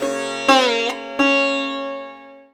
SITAR LINE46.wav